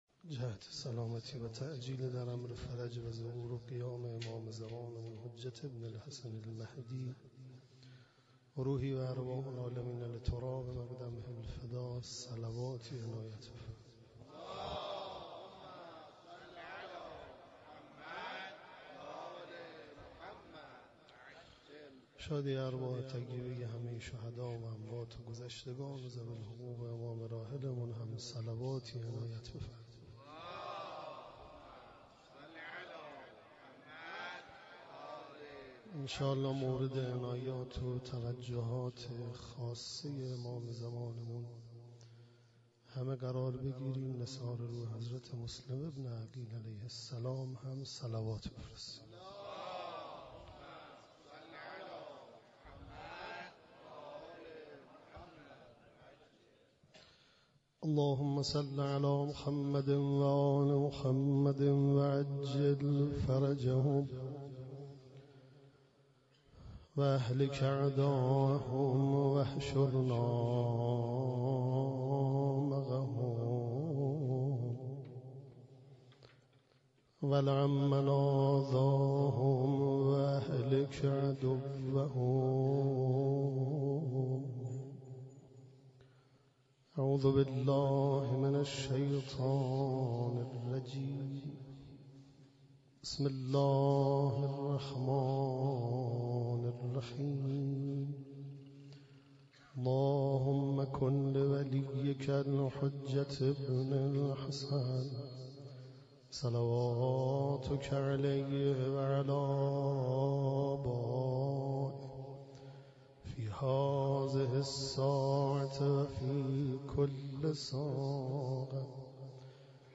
شبكه رادیویی تهران قرائت دعای پر فیض عرفه را به صورت مستقیم از آستان مقدّس حضرت عبدالعظیم حسنی علیه السّلام، پخش می كند.